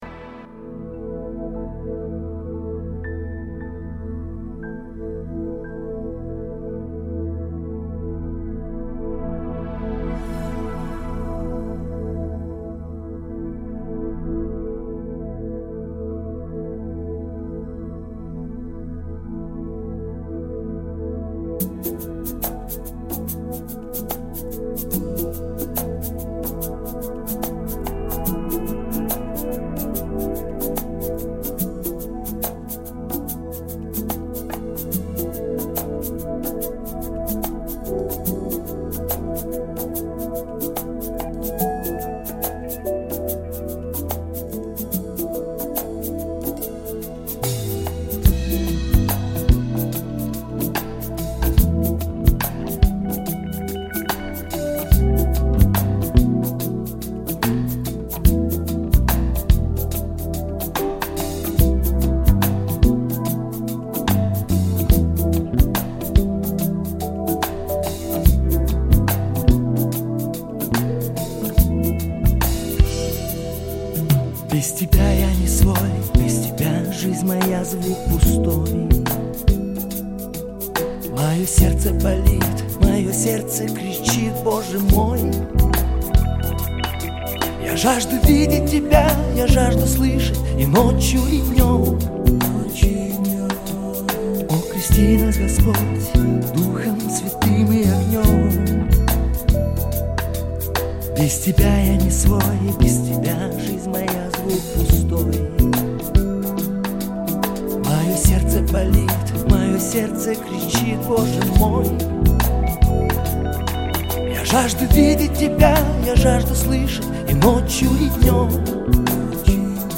песня
1792 просмотра 2589 прослушиваний 210 скачиваний BPM: 71